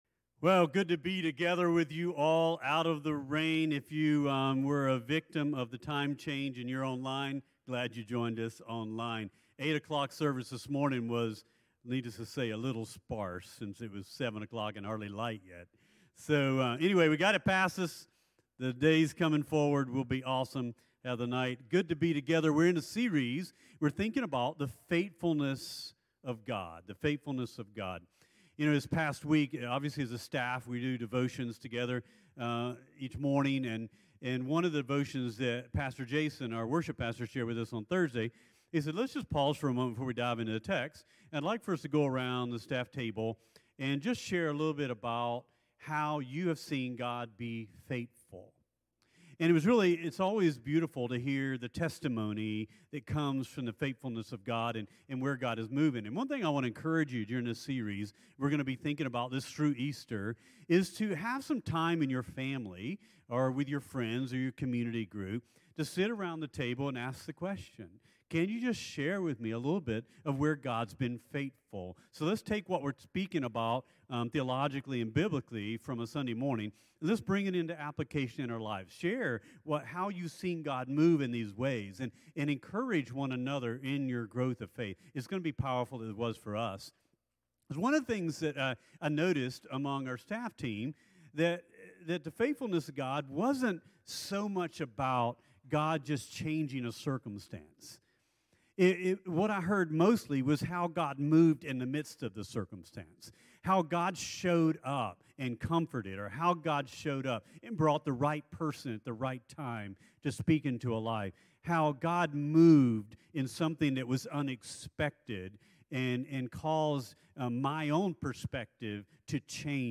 CTK-Clipped-Sermon-.mp3